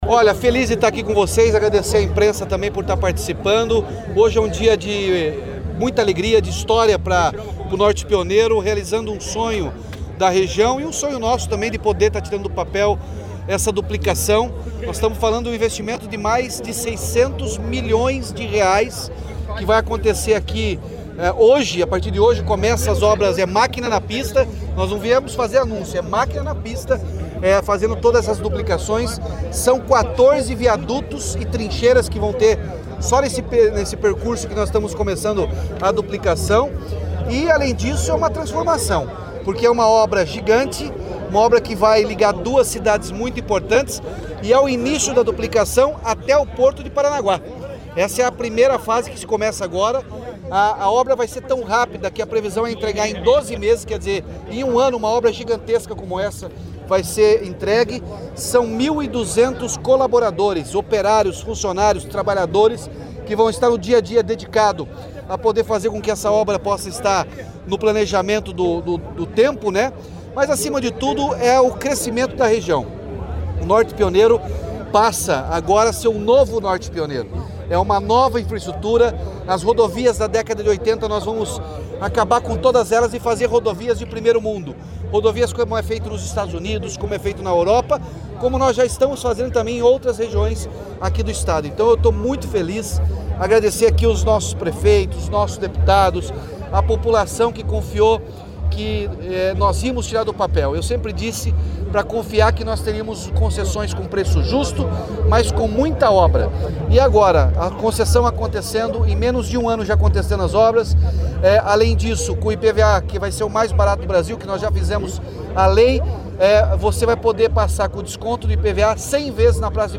Sonora do governador Ratinho Junior sobre a duplicação da BR-153 entre Jacarezinho e Santo Antônio da Platina